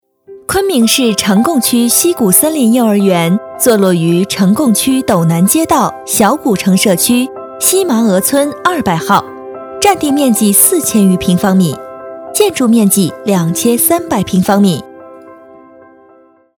女277-专题-明亮积极
女277角色广告专题 v277
女277-专题-明亮积极.mp3